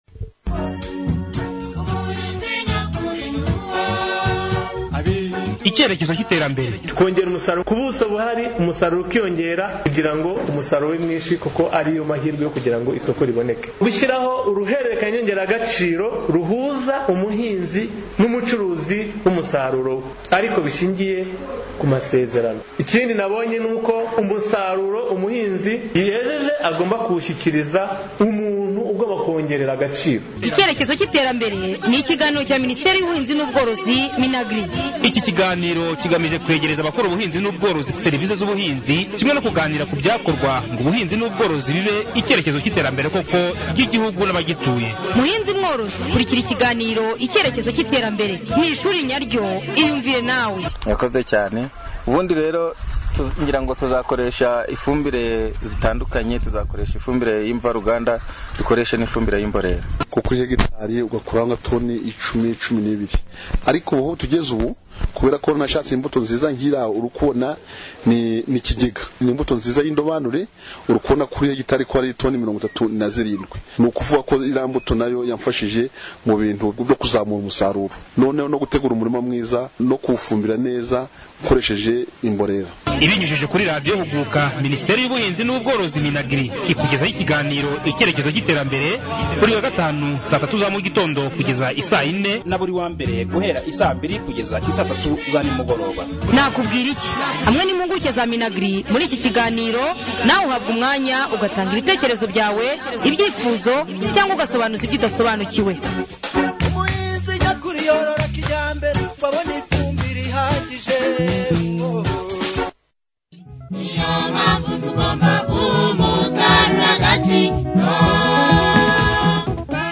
01. IKIGANIRO KU BURYO BWO GUSARURA